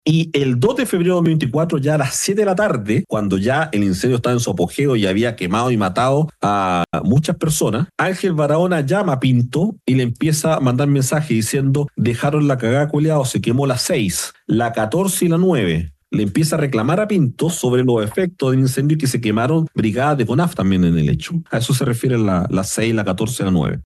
En la audiencia de formalización de cargos, el Ministerio Público entregó detalles de la acusación en contra de los cuatro nuevos detenidos por su participación en el megaincendio que se registró el 2 y 3 de febrero en la región de Valparaíso, cuya emergencia dejó 136 fallecidos.